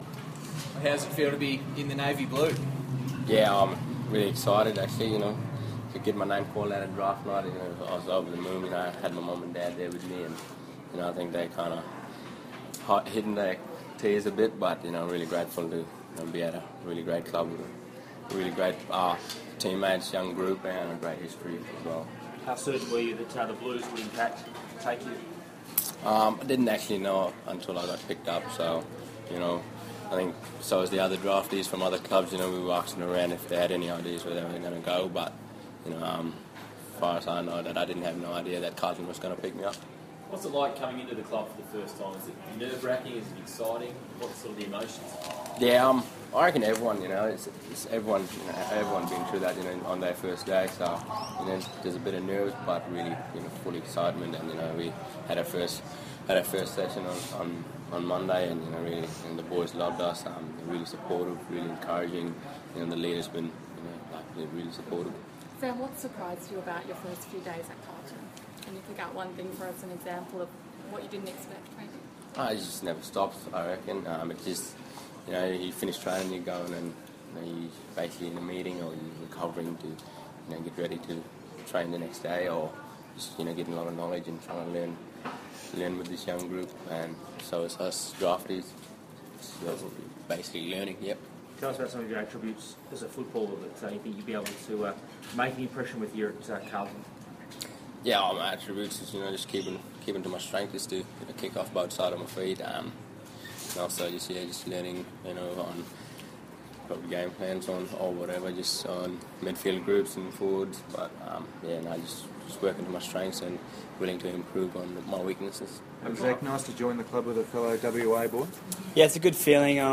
Sam Petrevski-Seton and Zac Fisher press conference - November 30
Carlton's No.6 draft pick Sam Petrevski-Seton and No.27 draft pick Zac Fisher speak to the media at Ikon Park.